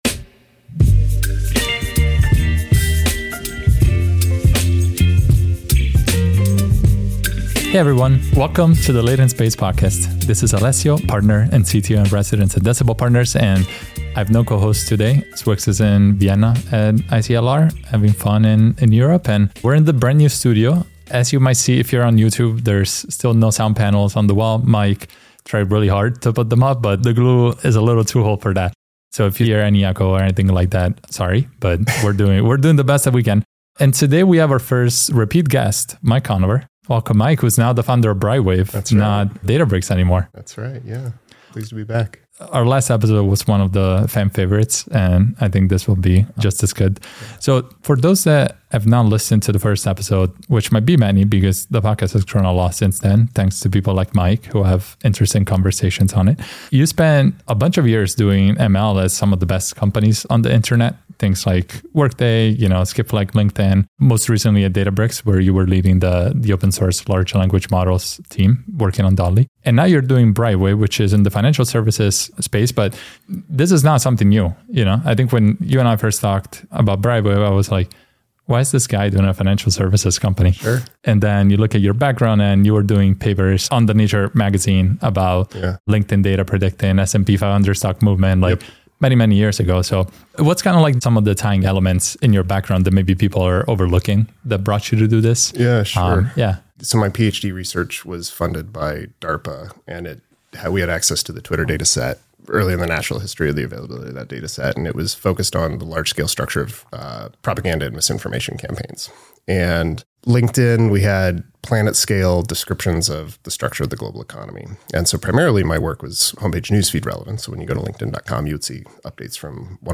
The conversation also dives into Brightwave's capabilities in combating information overload for finance professionals. They explore challenges in data handling and the balance between AI and human decision-making, particularly in hedge funds.